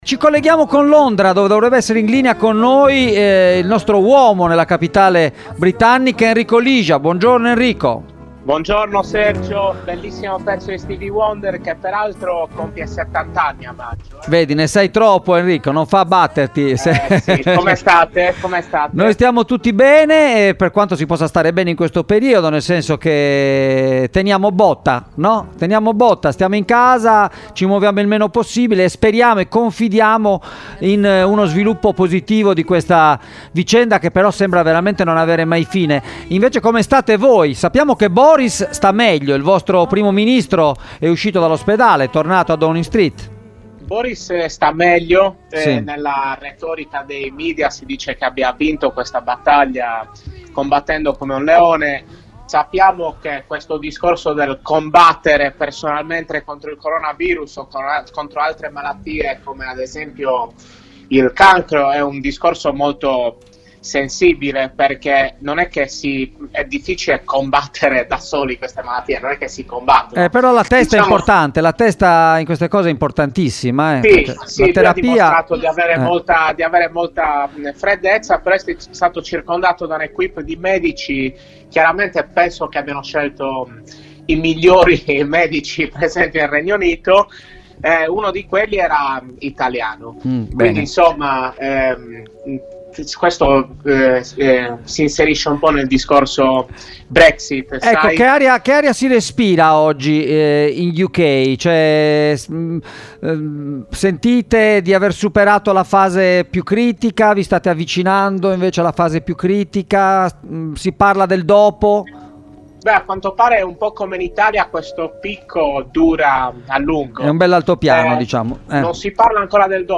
In diretta da Londra